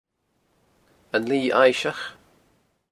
Gaelic name: Lighe Aillseach, An Name in Original Source: Lighe Aillseach, An English meaning: The pool of Lochalsh Placename feature: Sea pool Notes: Pronounced Un Lee Aye-shach, this refers to the area of water between na h-Eileanan Chròlaig, Broadford, Scalpay, Lochcarron and Kishorn.